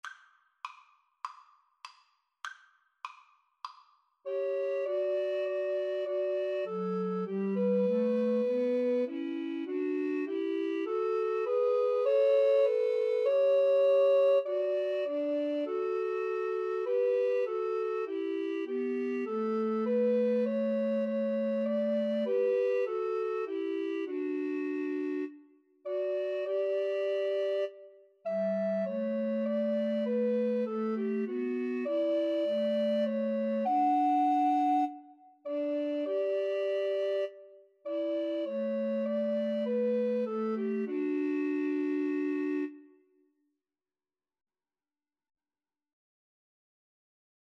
Alto RecorderTenor RecorderBass Recorder
4/4 (View more 4/4 Music)